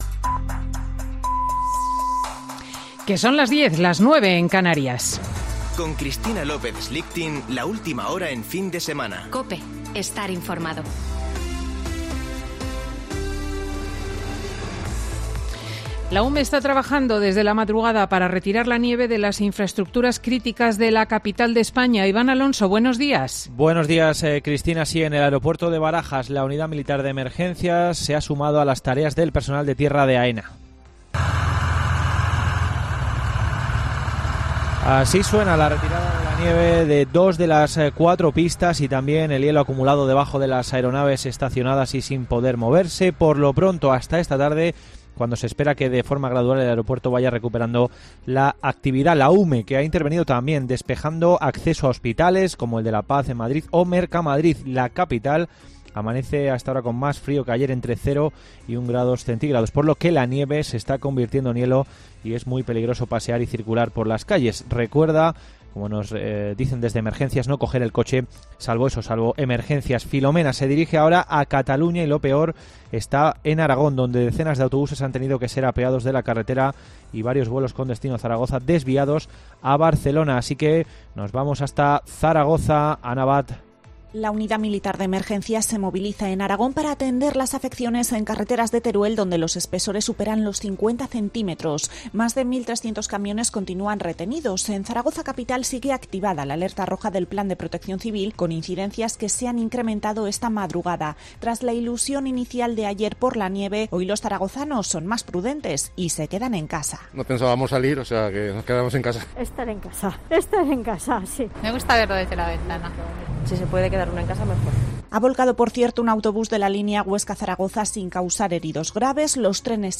Boletín de noticias COPE del 10 de enero de 2021 a las 10.00 horas